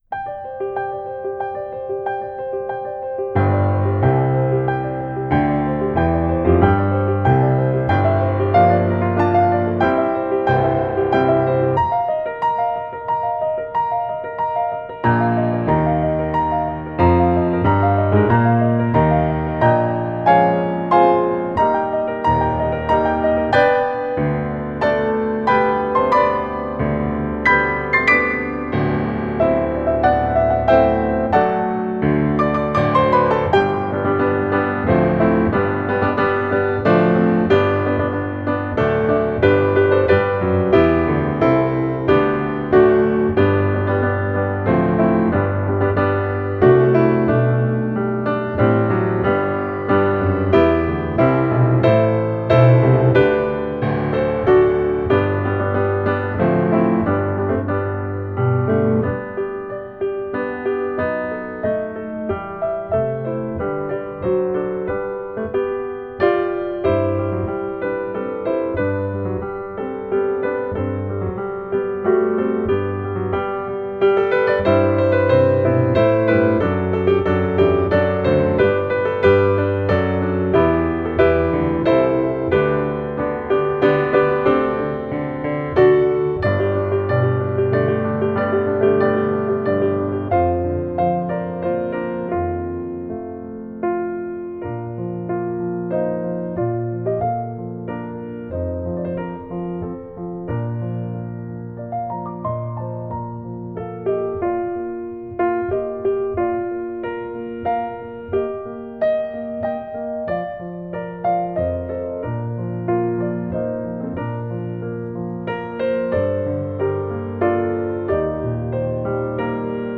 Voicing: Piano Collection